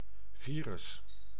Ääntäminen
Synonyymit ultravirus Ääntäminen France: IPA: [vi.ʁys] Haettu sana löytyi näillä lähdekielillä: ranska Käännös Konteksti Ääninäyte Substantiivit 1. virus {n} biologia Suku: m .